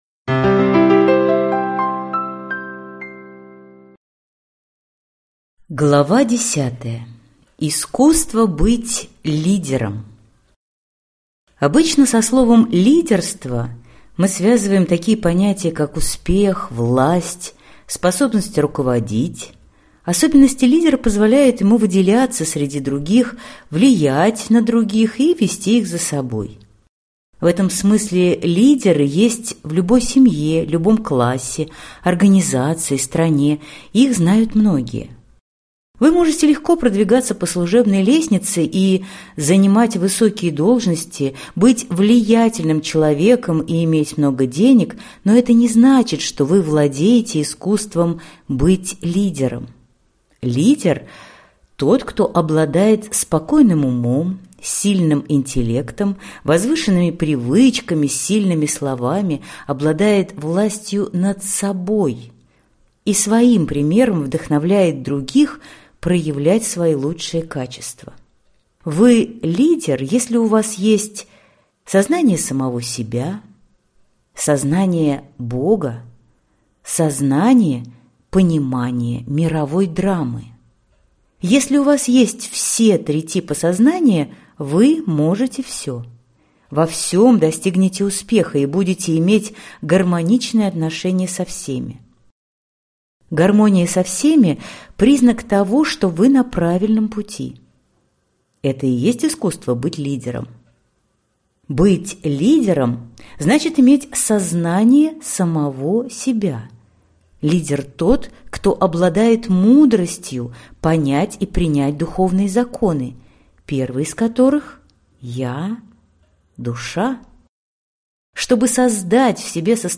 Аудиокнига Жизнь как искусство. Часть 2 | Библиотека аудиокниг